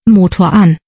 Diesen Logschen Schalter dann in Sprachausgabe verwendet um Sprachausgabe für Glühmeldung zu geben.